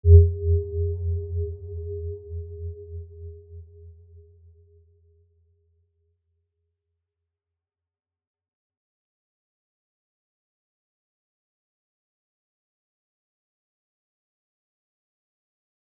Aurora-G2-mf.wav